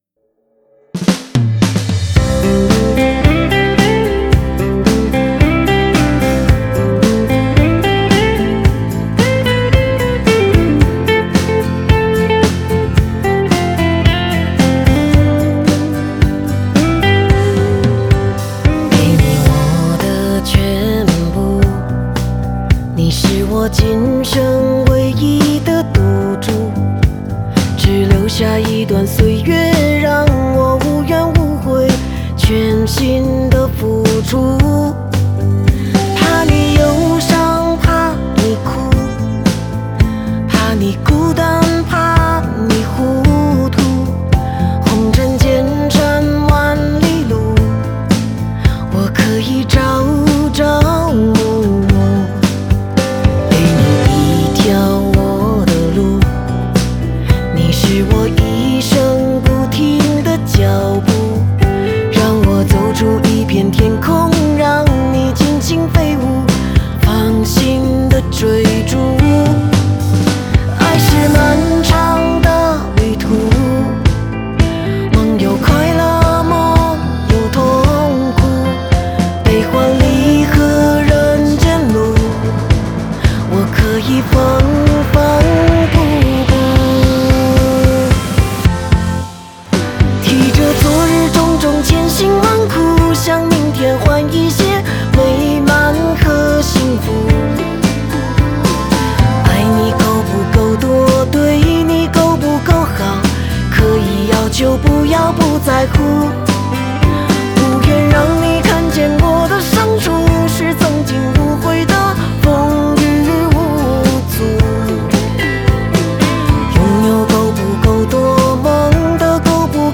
Ps：在线试听为压缩音质节选，体验无损音质请下载完整版
女版